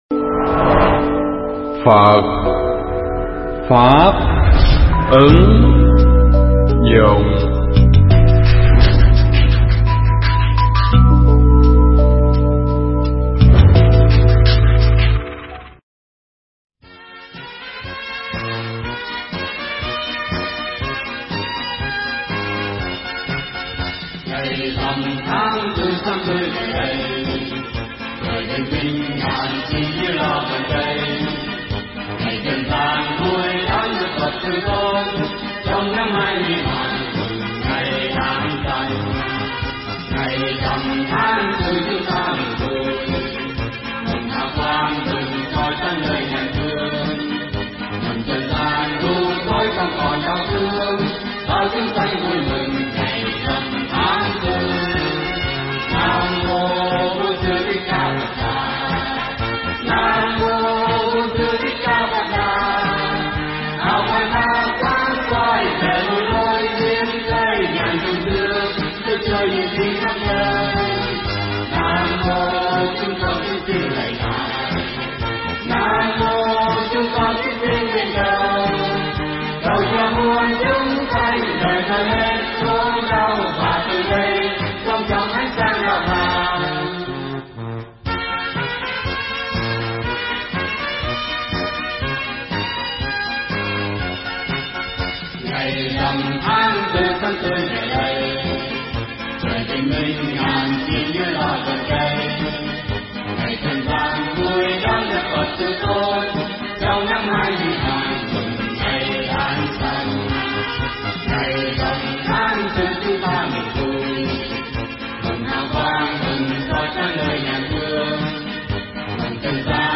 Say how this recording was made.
thuyết giảng trong đại lễ kính mừng Phật Đản lần thứ 2635